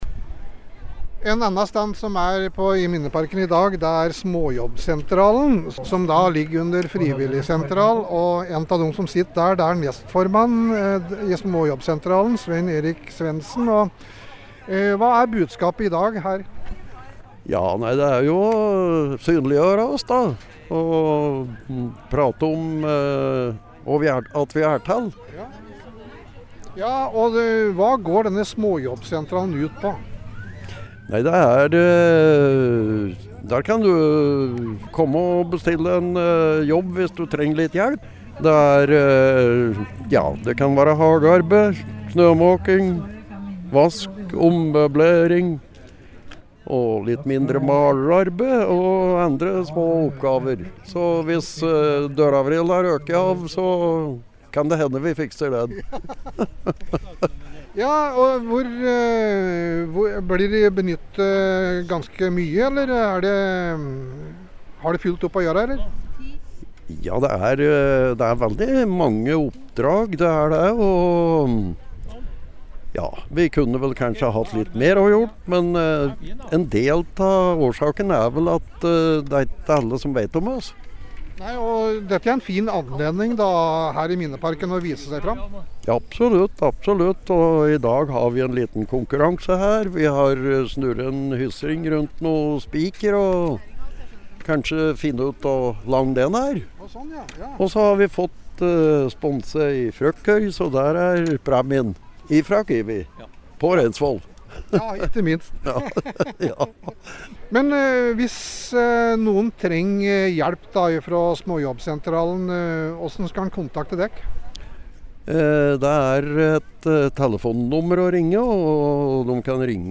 Frivillighetsdag i Minneparken på Raufoss
Vi var også der og snakket med noen av utstillerne.